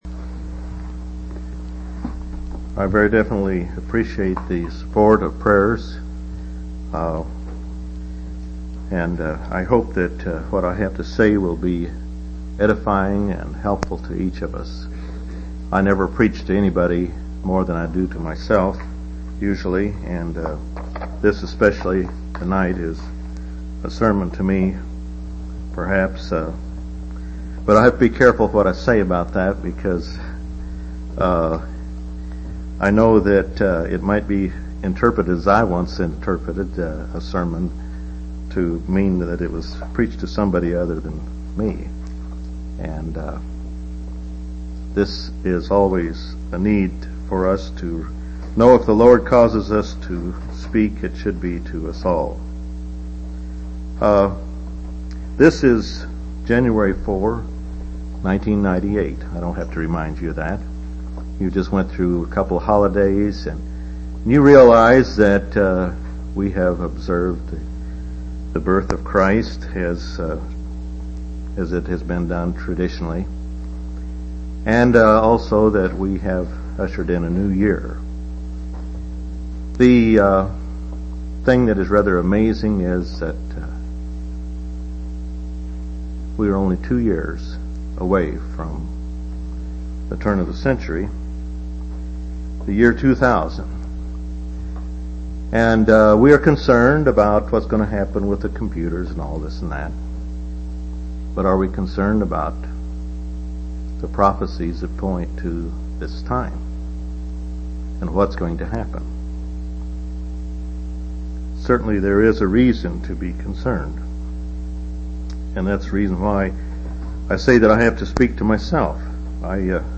1/4/1998 Location: East Independence Local Event